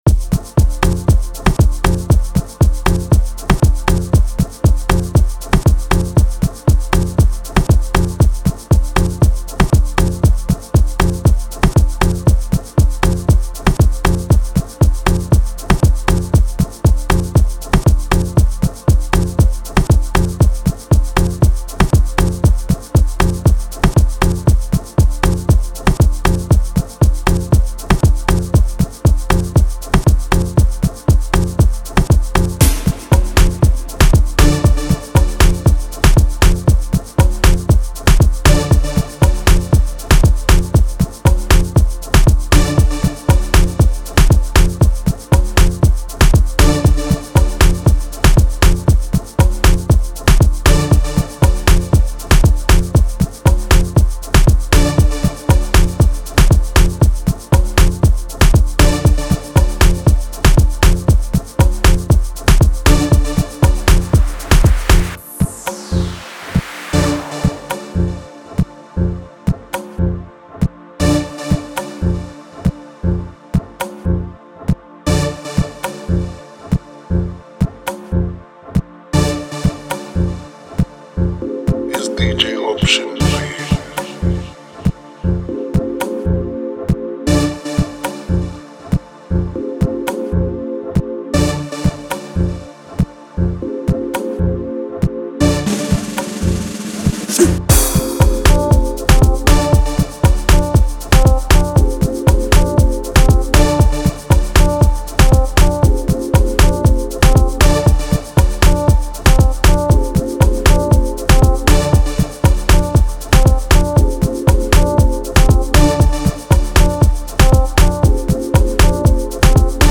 06:33 Genre : House Size